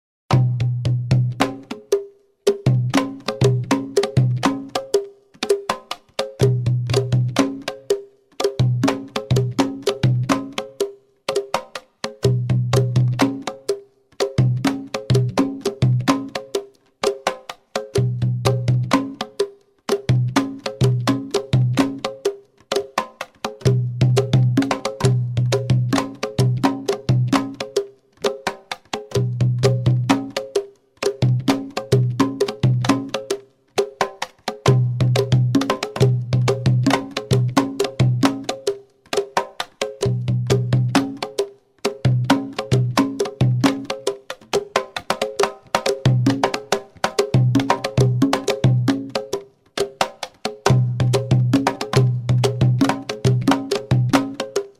ODDÚA (oro seco)